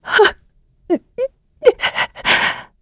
Isolet-Vox_Sad2_kr.wav